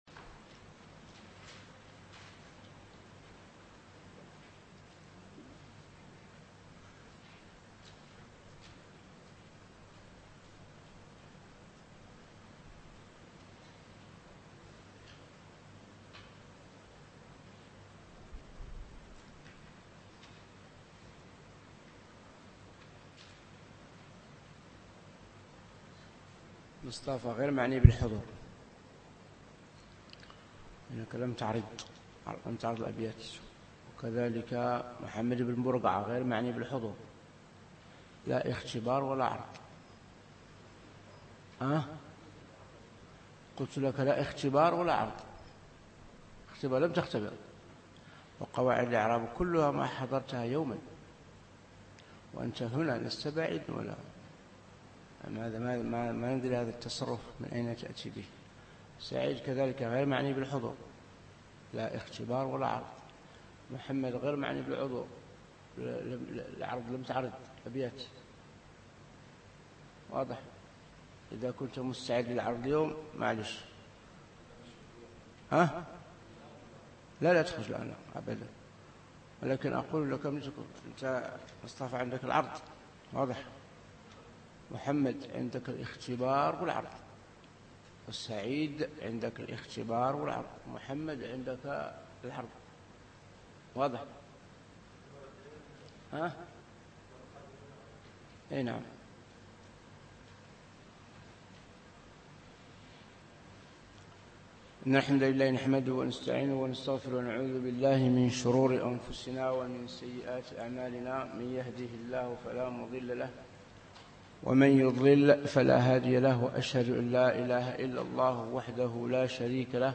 شرح الأصول من علم الأصول الدرس 1